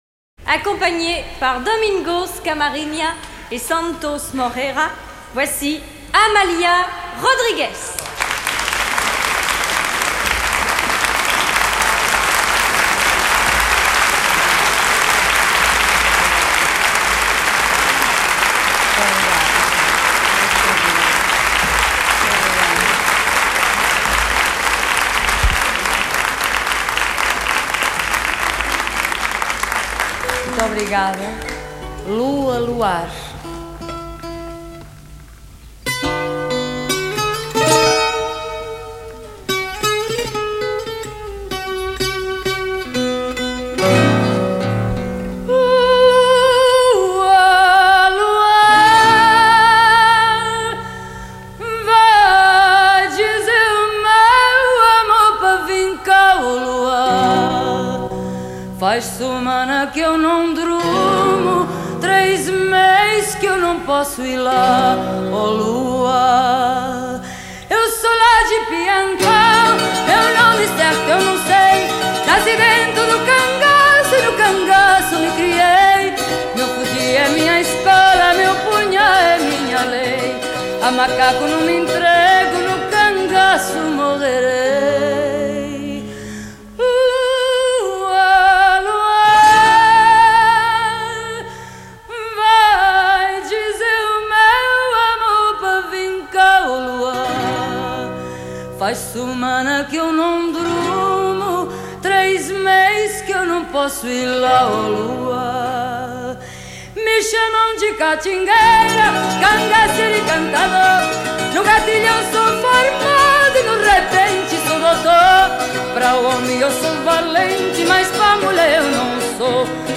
chant
guitare portugaise
guitare classique.